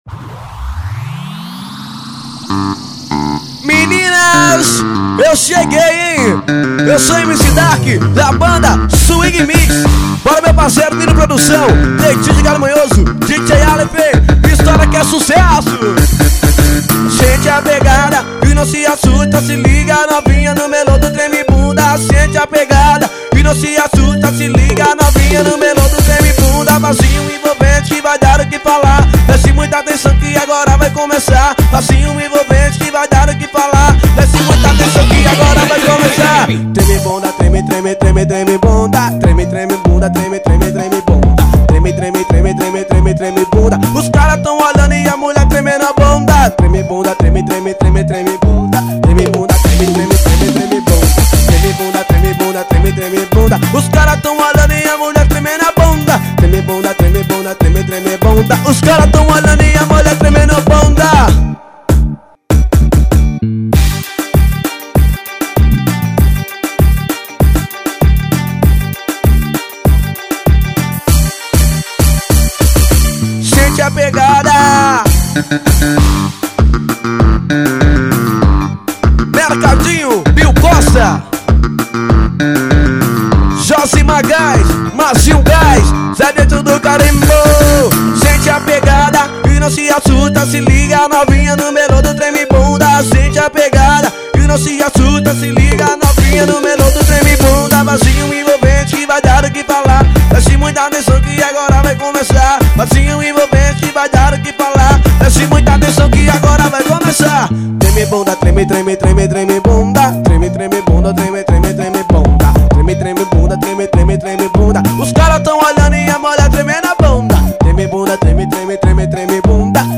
AO VIVO..